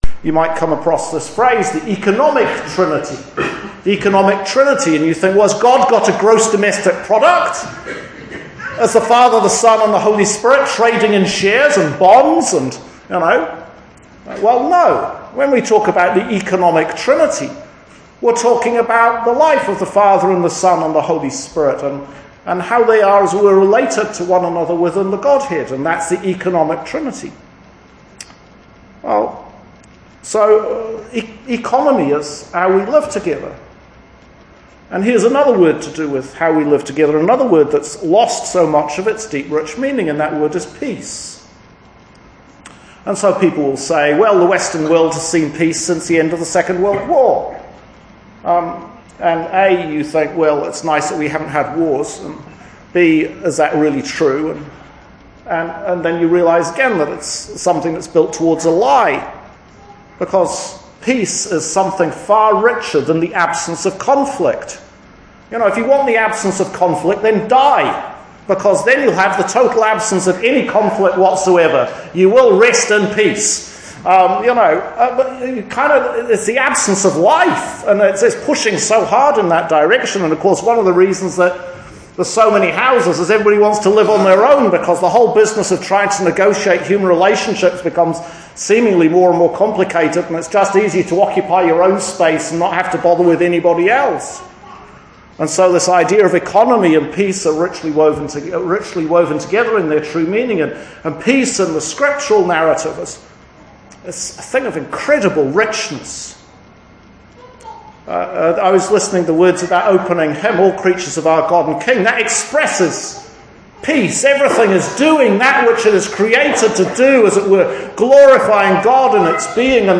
Sermon notes – Sunday July 22nd, 2018 8 after Trinity – Year B